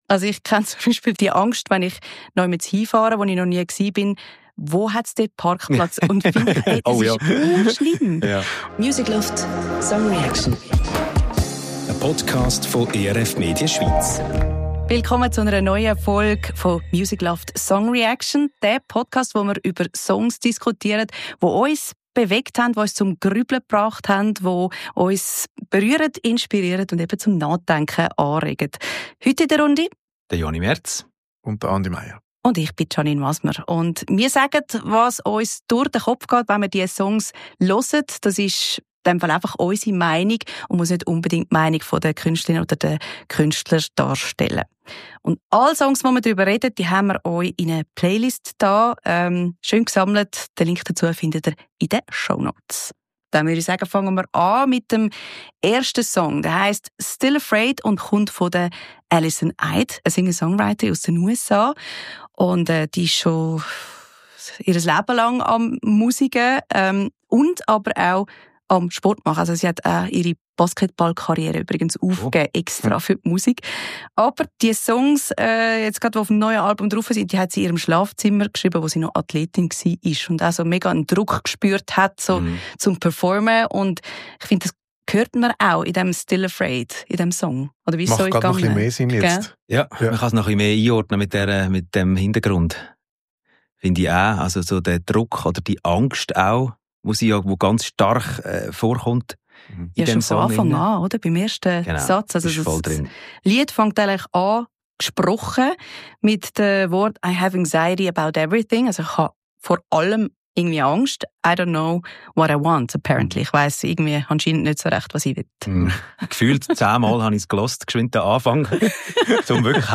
Zu Dritt sitzen wir im Studio und diskutieren über Songs, die uns berührt und inspiriert haben.